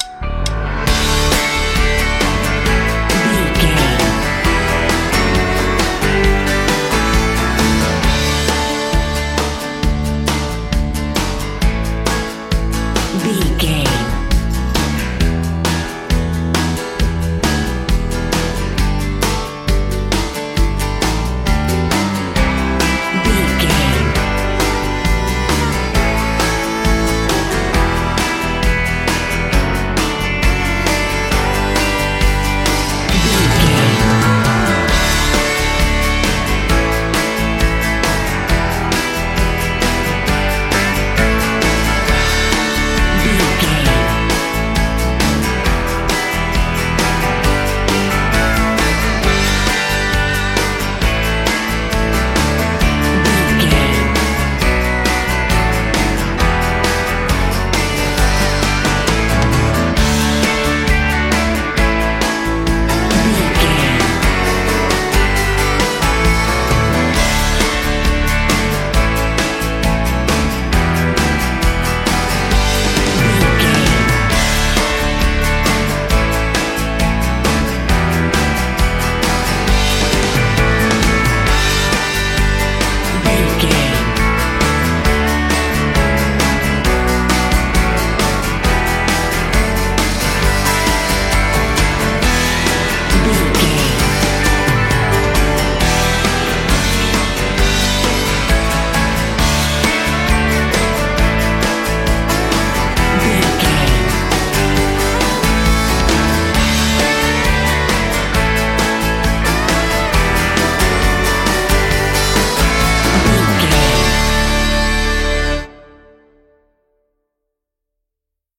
Ionian/Major
D
electric guitar
drums
bass guitar
violin
Pop Country
country rock
bluegrass
happy
uplifting
driving
high energy